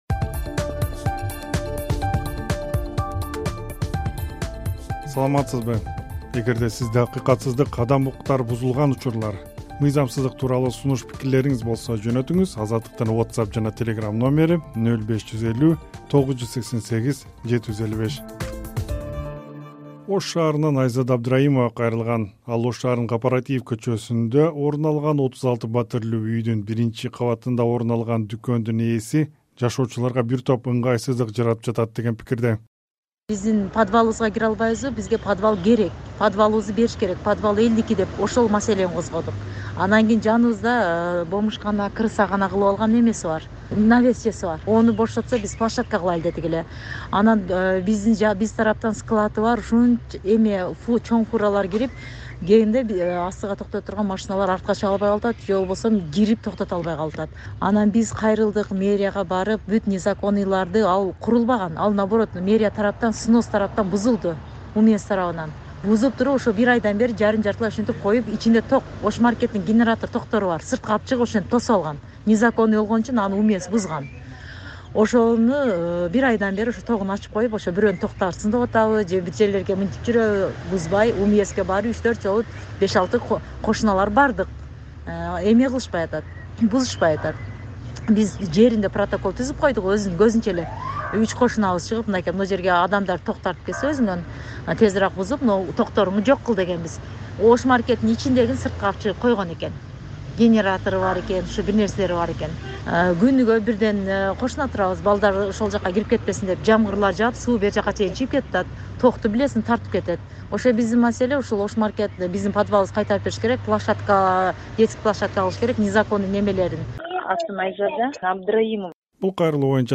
Табиятка таштанды калтырбоону өтүнгөн фермер. Уурулук көбөйгөн Ак-Ордо конушу. Дагы башка сунуш-пикирлер "Эл үнү" радио берүүсүнүн кезектеги чыгарылышында.